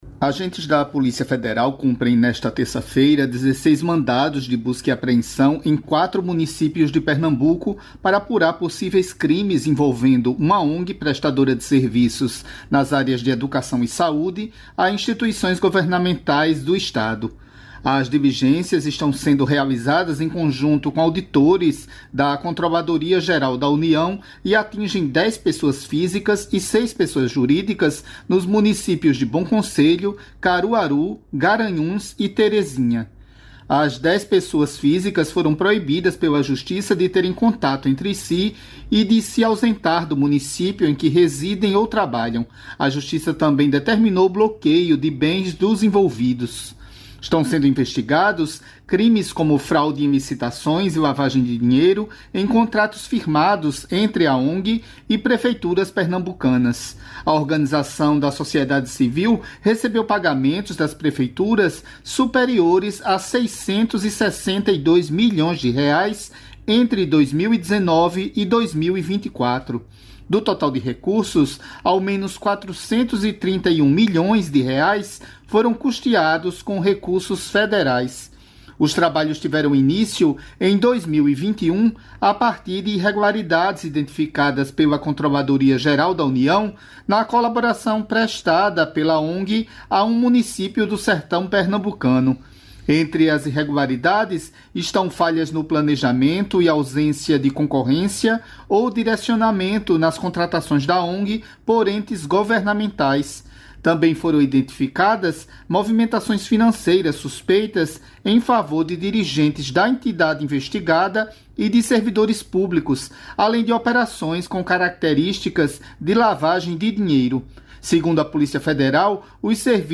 Os recentes ataques de Israel ao Irã fazem parte do projeto israelense de expulsão da população palestina, afirmam especialistas ouvidos pela reportagem.